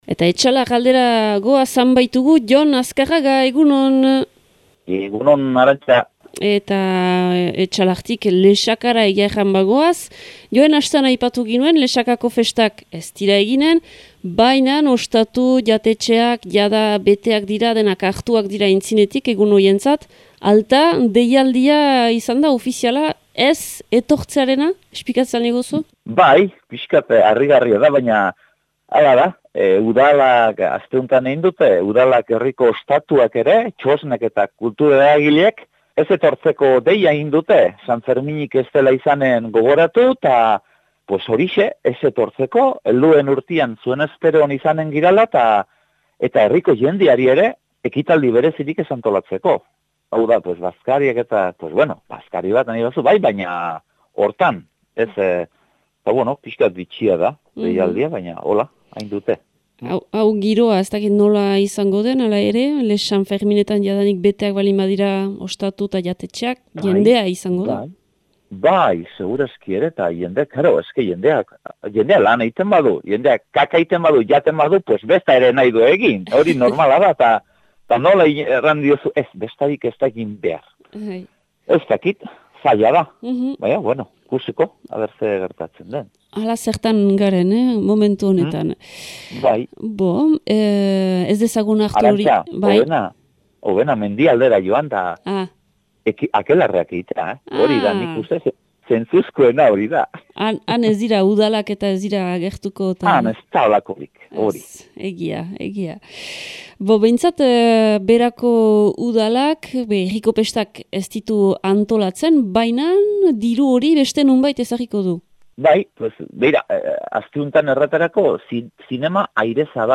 Uztailaren 2ko Etxalar eta Baztan aldeko berriak